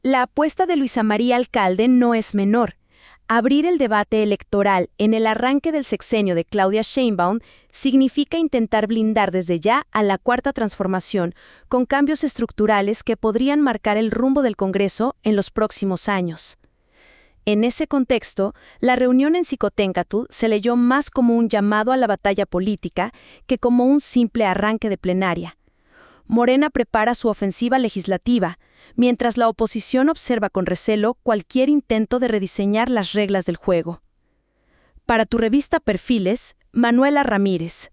En la antigua sede del Senado, en la calle de Xicoténcatl, Alcalde dejó claro que el siguiente periodo legislativo no será de mero trámite.